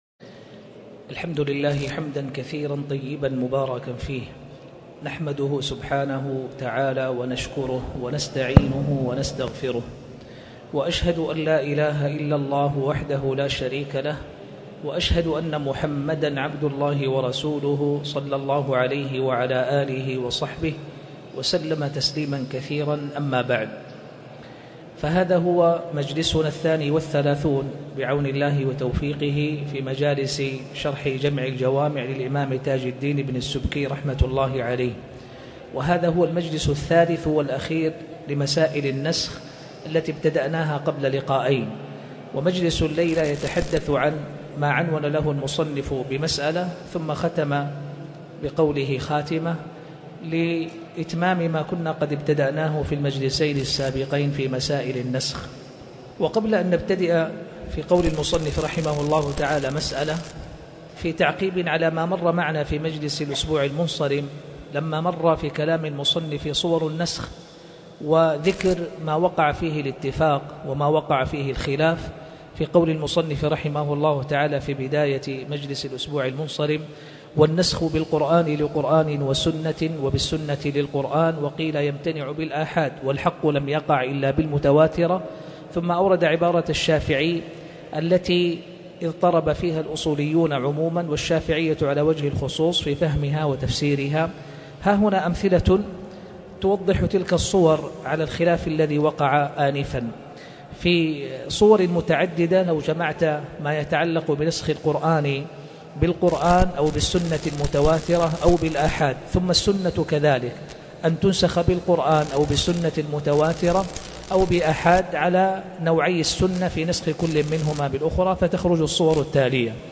تاريخ النشر ١١ محرم ١٤٣٨ هـ المكان: المسجد الحرام الشيخ